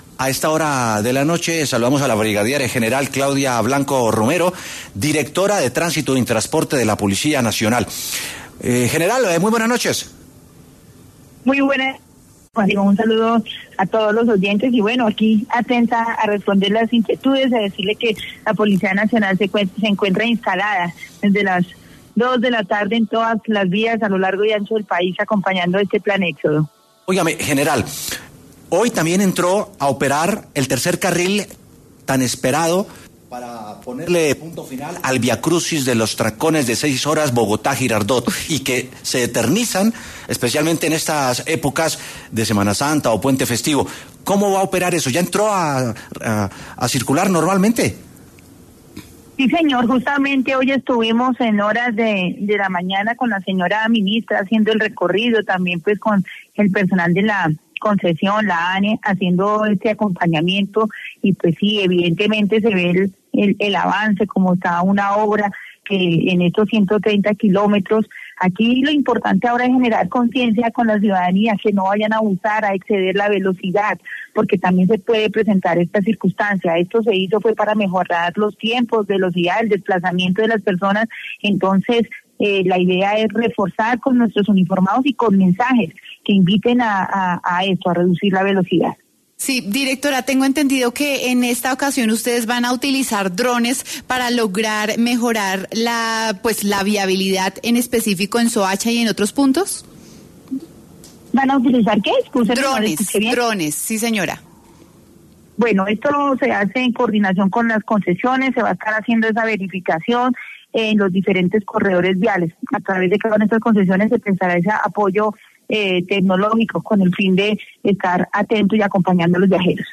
En W Sin Carreta consultamos con la coronel Susana Blanco, directora de Tránsito y Transporte de la Policía Nacional, algunas recomendaciones para que evite contratiempos.